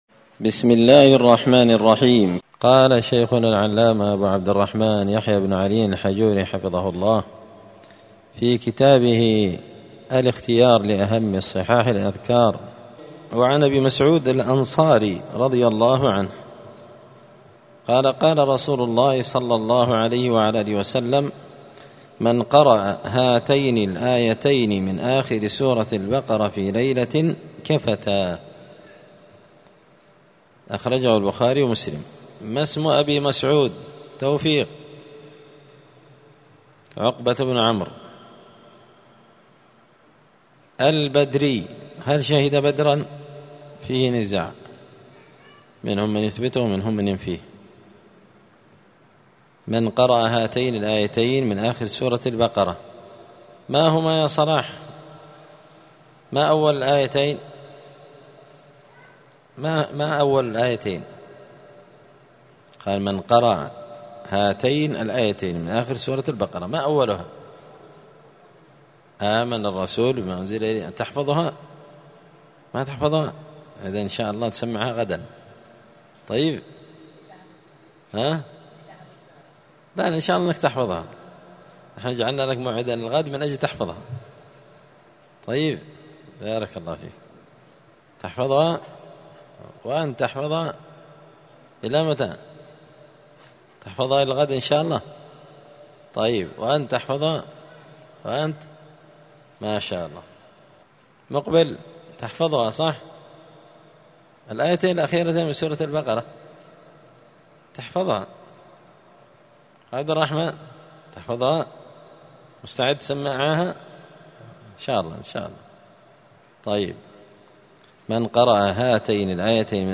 *{الدرس السابع عشر (17) الحديث الحادي عشر من أذكار الصباح والمساء}*